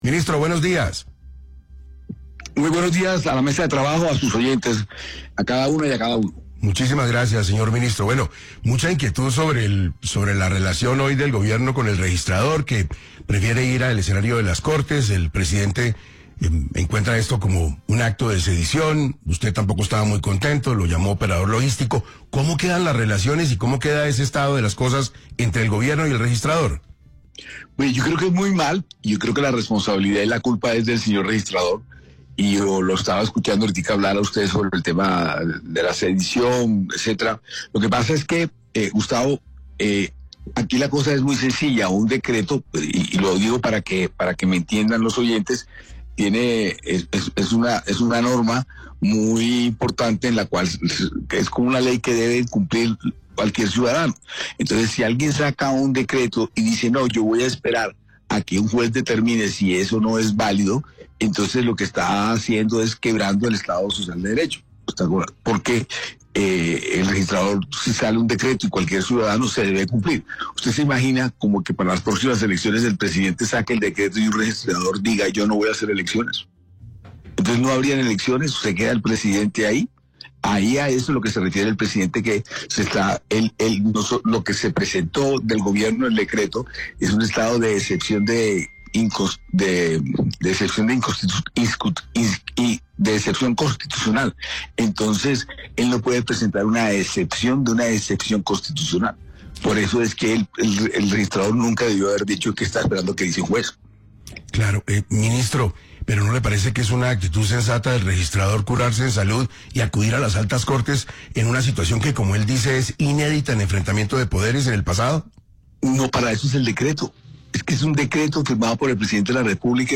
En entrevista con 6AM de Caracol Radio, el ministro del Interior, Armando Benedetti, arremetió contra el registrador nacional, Hernán Penagos, por su decisión de acudir al Consejo de Estado, la Procuraduría y la Agencia Nacional de Defensa Jurídica del Estado antes de aplicar el decreto presidencial que convoca a una consulta popular sobre temas sociales.